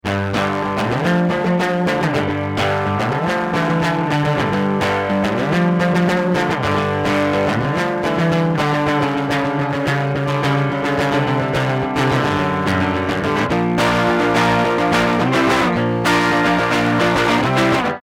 Note: In these examples, Version A is with no effect, Version B is with mild saturation, and Version C is…absolutely disgusting.
Rhythm-Guitar-Medium-Crunch_01.mp3